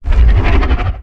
Growl3.wav